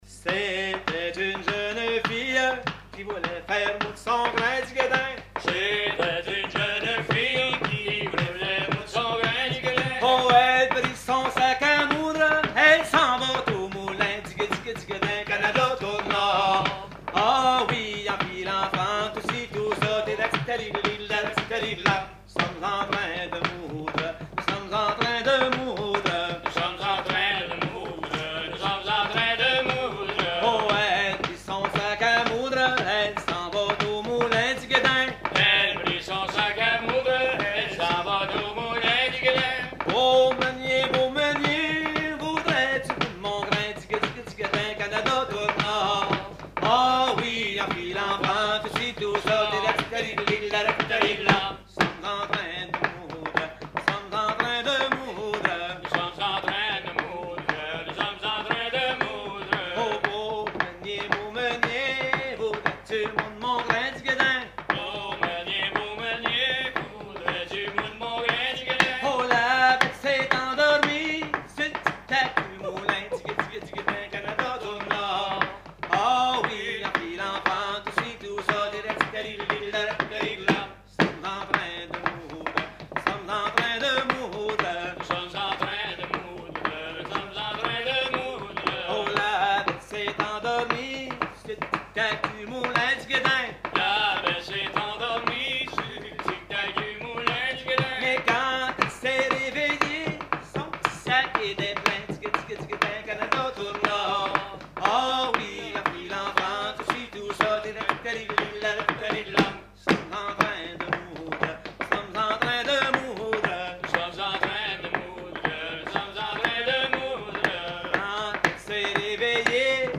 Veillée québécoise à la Ferme du Vasais
Pièce musicale inédite